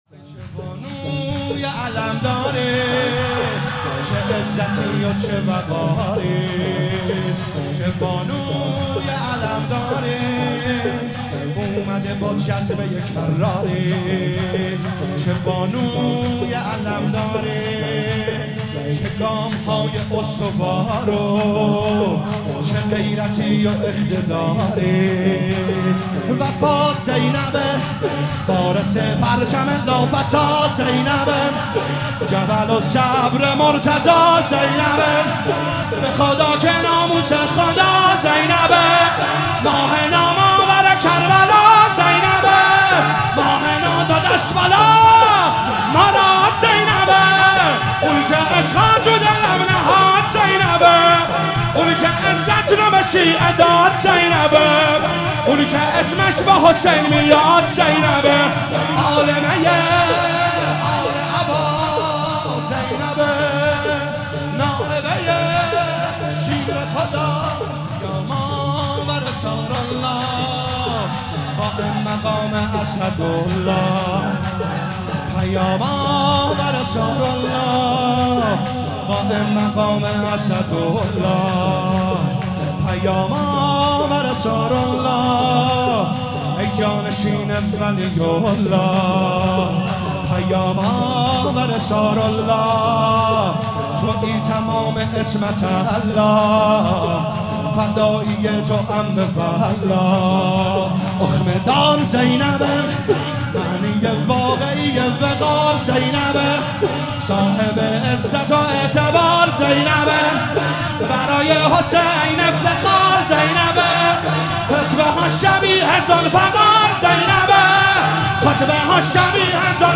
ایام فاطمیه 1440 | محفل عزاداران حضرت زهرا (س) شاهرود